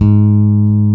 -MM JAZZ G#3.wav